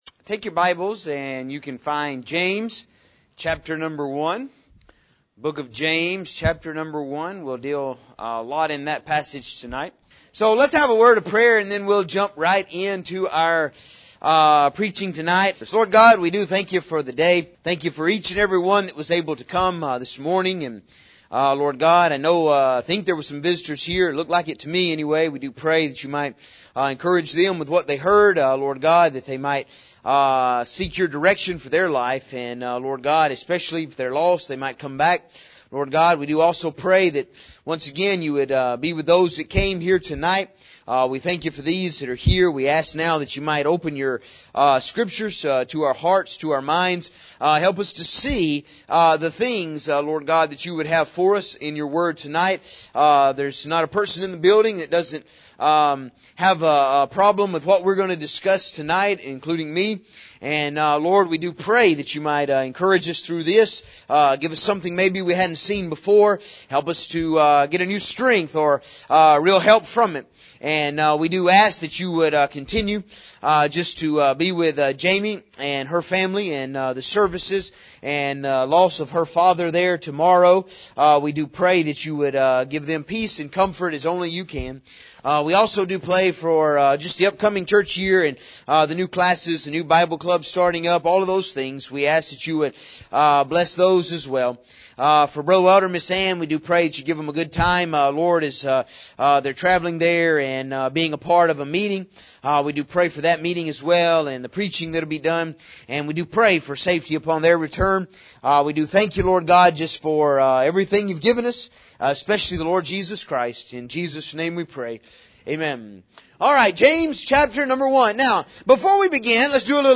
As we will see in this sermon lust is something that is in our flesh and when it is joined with temptation it causes sin.